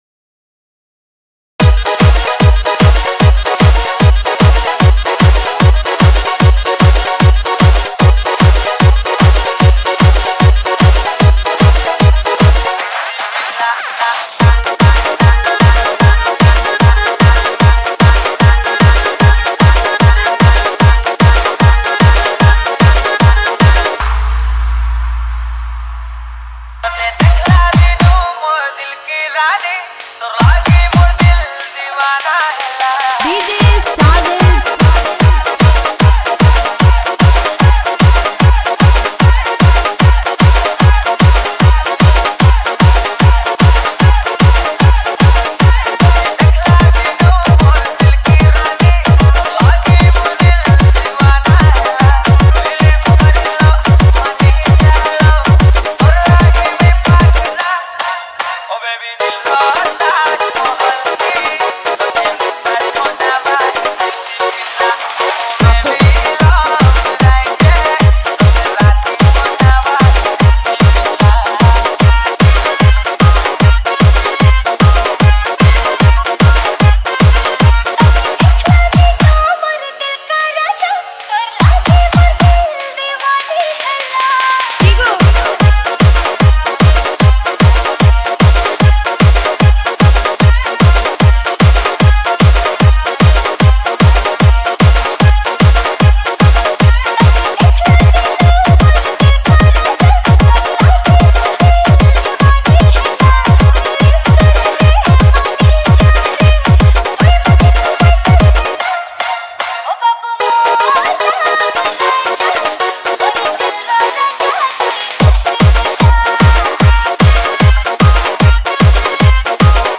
Category:  Sambalpuri New Dj Song 2019